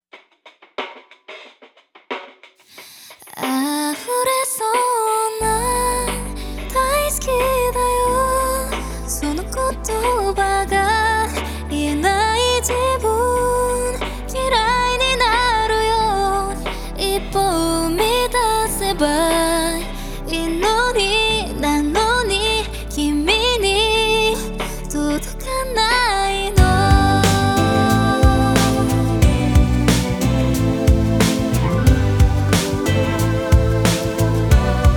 Жанр: Поп музыка / Соундтрэки